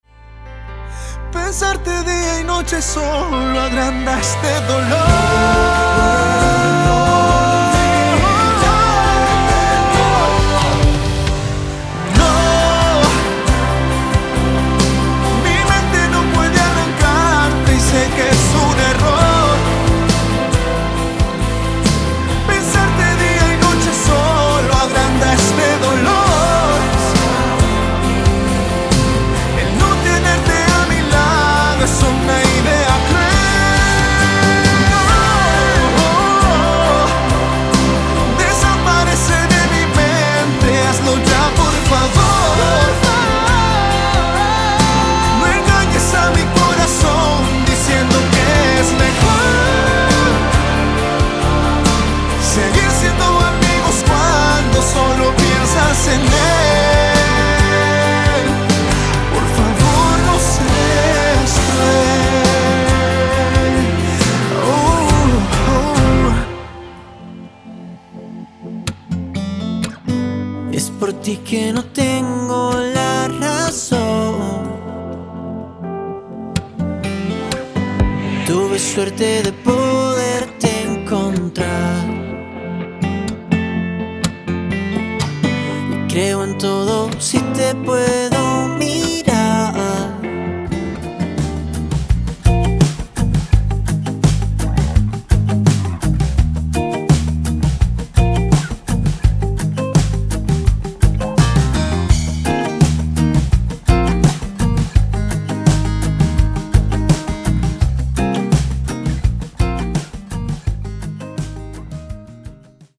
Pop_Samples_bv6X1qRoeC.mp3